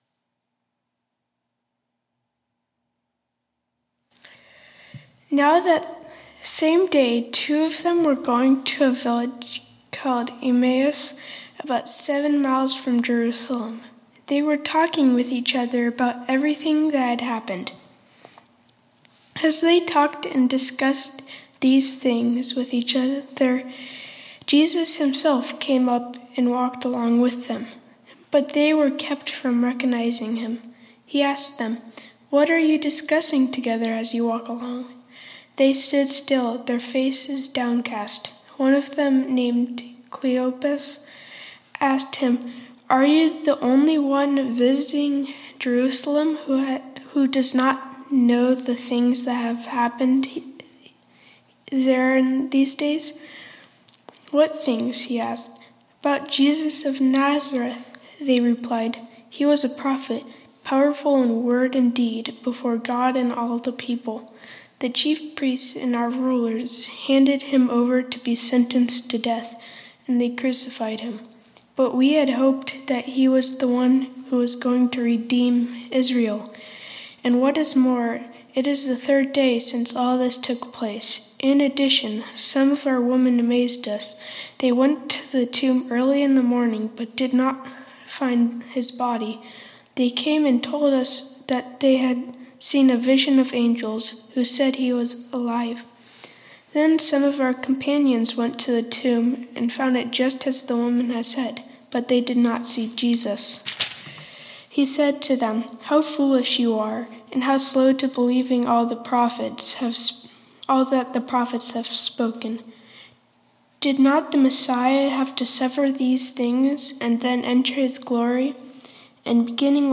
2020-04-26 Sunday Service
Scripture reading, Teaching, and Lord’s Prayer